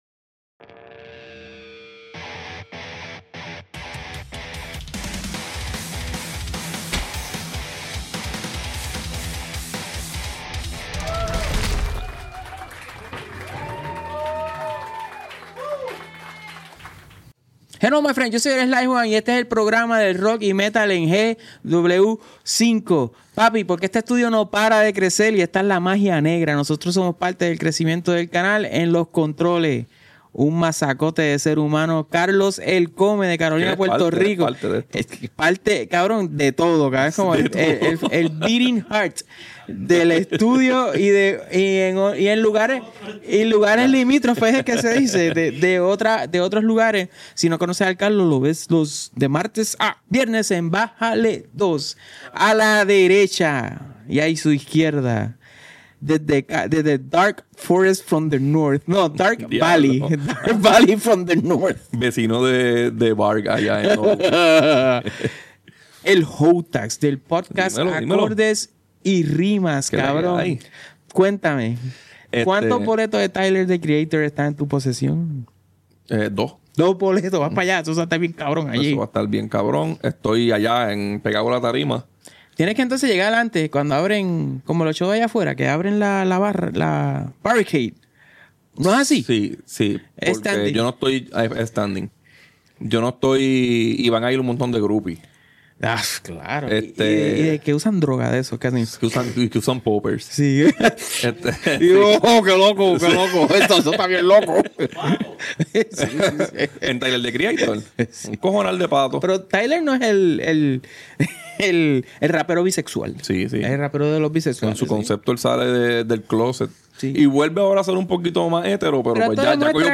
En este episodio de Power Rock, directamente desde los estudios de GW5, nos tiramos una conversación bien heavy sobre el nuevo disco de Moths 🪰🔥. Hablamos de su sonido más maduro, sus letras intensas y, por supuesto, su gira arrasando tarimas por todo USA.